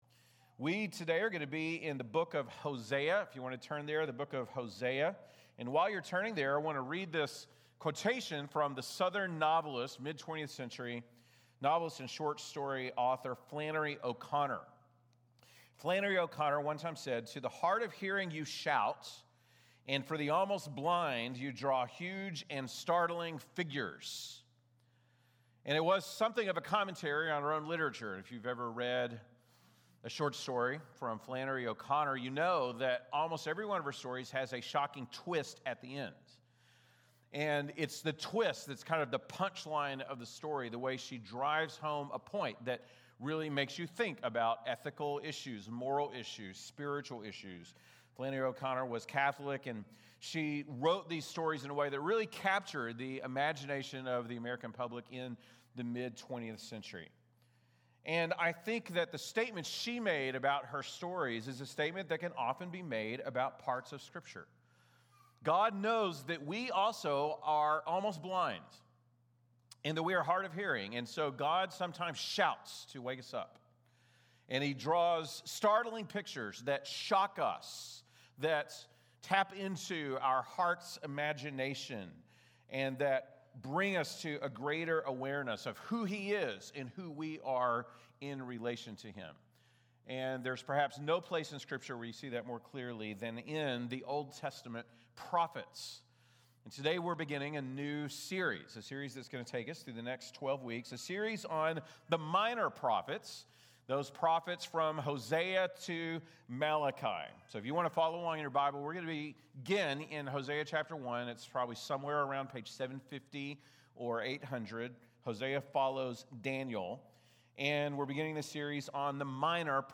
( Sunday Morning )